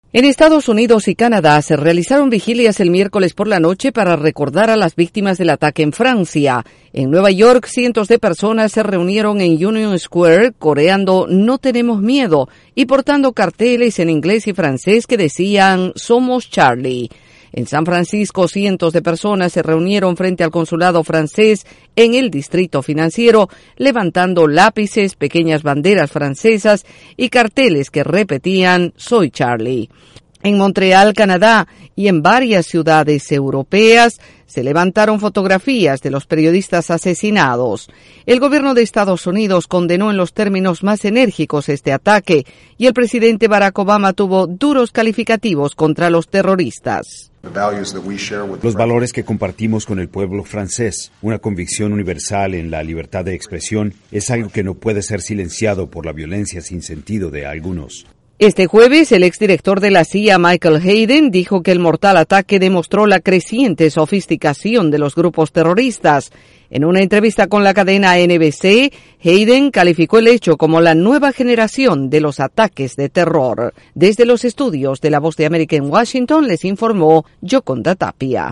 Vigilias en todo el mundo en honor de las víctimas francesas. Desde la Voz de América en Washington